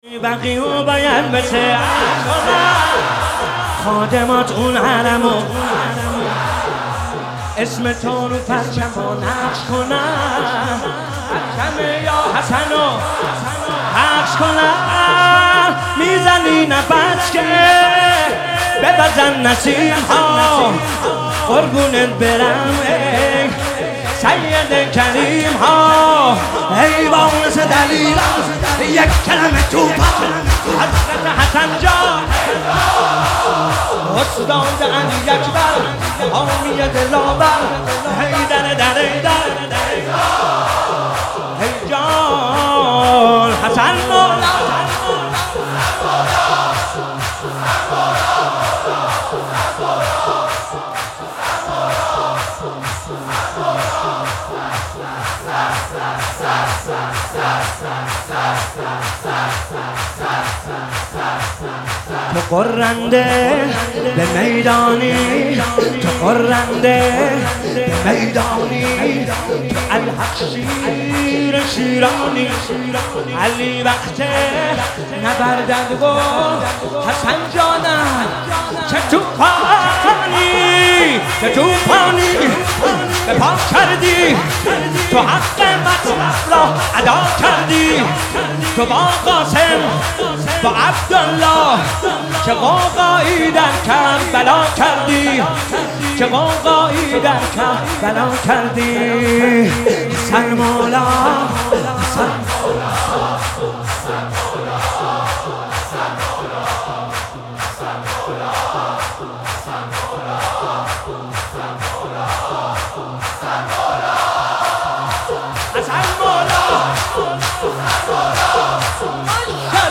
نوحه
واحد(حسین طاهری)
شور(حسین طاهری)